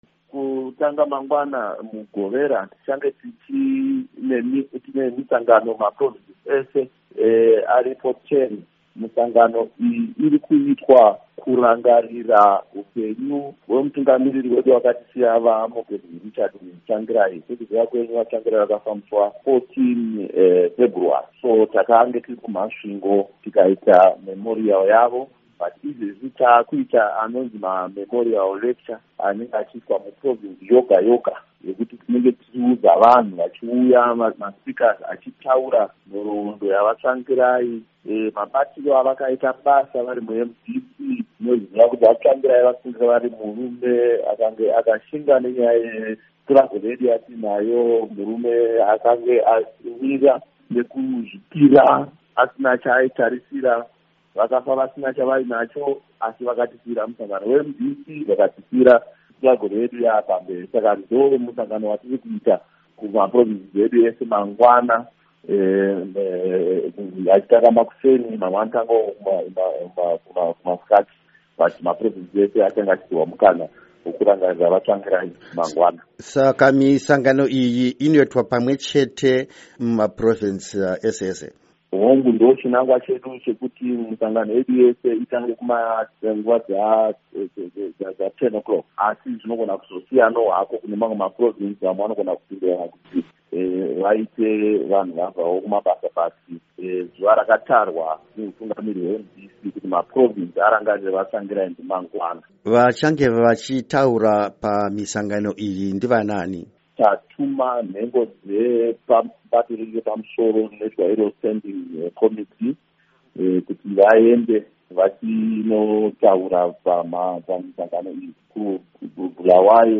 Hurukuro naVaCharlton Hwende